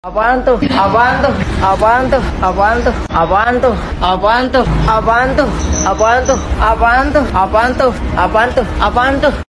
Efek Suara Apaan Tuh
Kategori: Suara viral
Keterangan: Sound effects Apaan Tuh/ Apaantuh... adalah suara meme viral di Indonesia, sering digunakan dalam video editan.
efek-suara-apaan-tuh-id-www_tiengdong_com.mp3